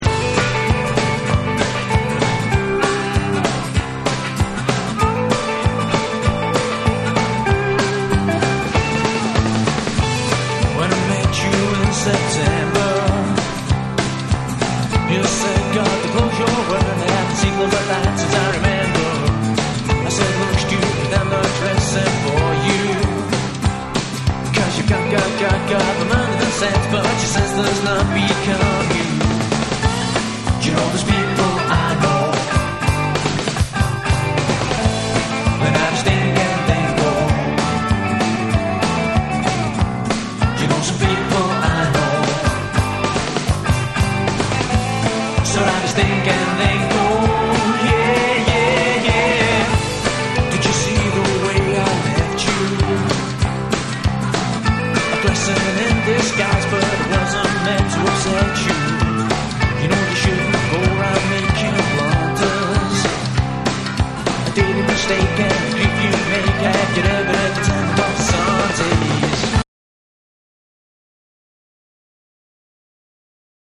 NEW WAVE & ROCK